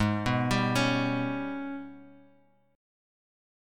G#mM11 chord